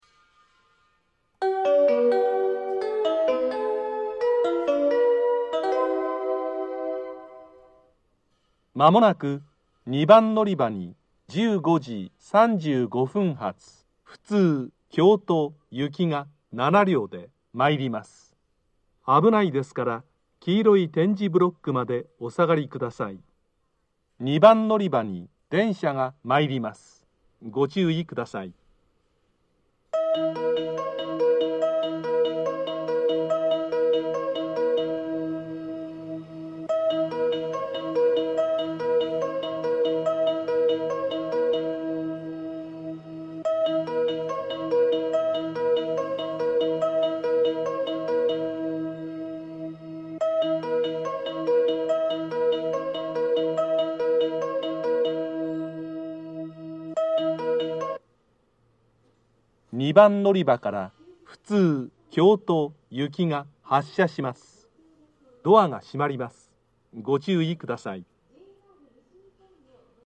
（男性）
到着放送は流れませんでした。
接近放送・発車放送